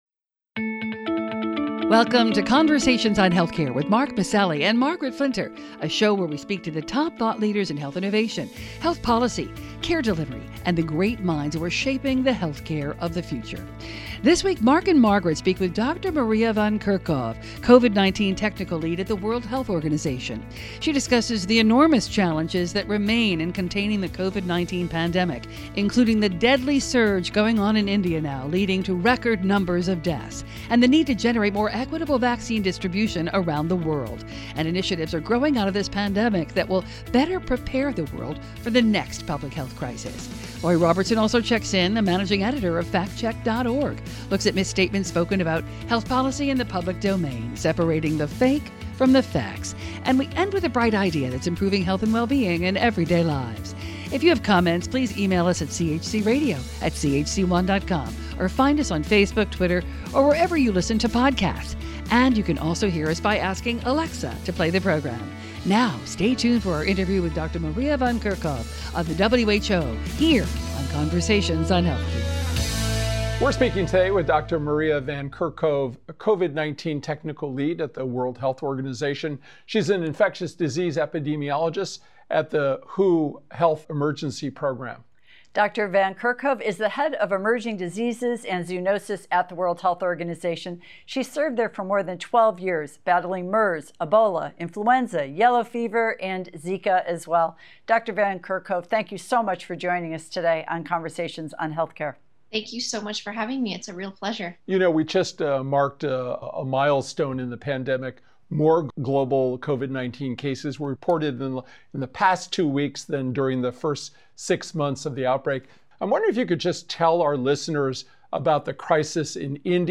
speak with Dr. Maria Van Kerkhove, COVID-19 Technical Lead at the WHO. They discuss the world’s lack of readiness and supply chain infrastructure to confront the pandemic. She says the lessons learned from this collective ‘trauma’ is sparking new efforts to build more robust surveillance and response systems for emerging pathogens, the concerning new variant out of India, and the need to accelerate global vaccinations to stop the continued spread of the pandemic.